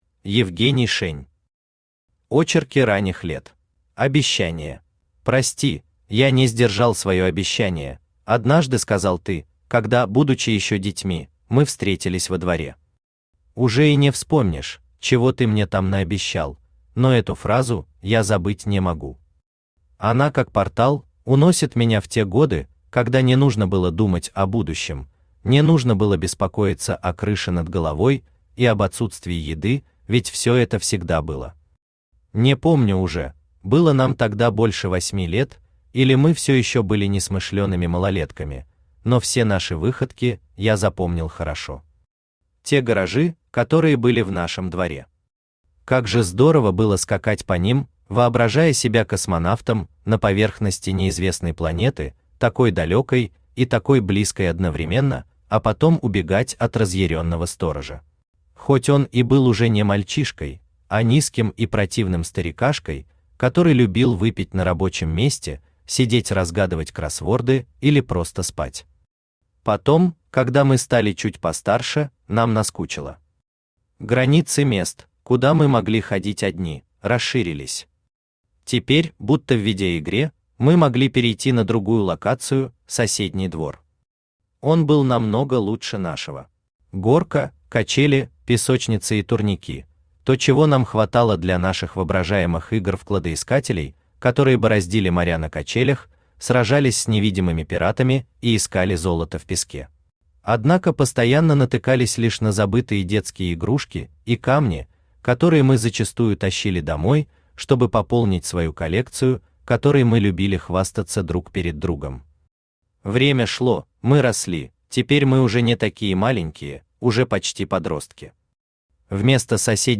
Aудиокнига Очерки ранних лет Автор Евгений Шень Читает аудиокнигу Авточтец ЛитРес.